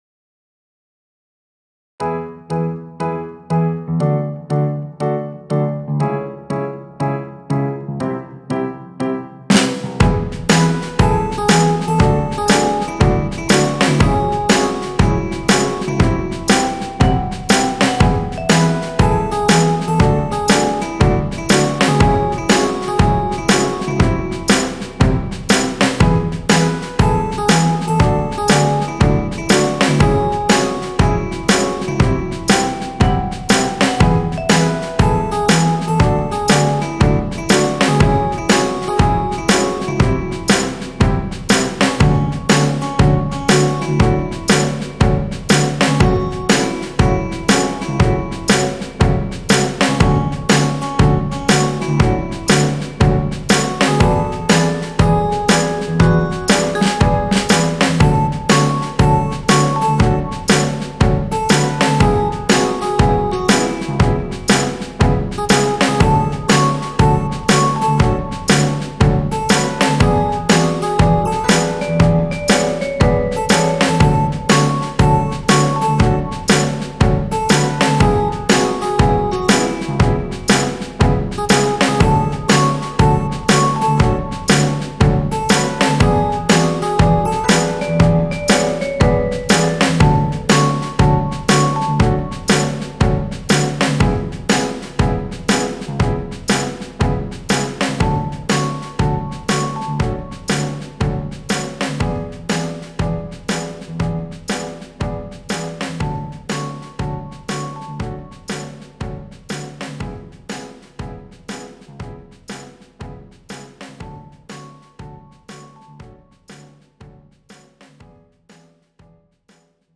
BGM
ジャズロング明るい穏やか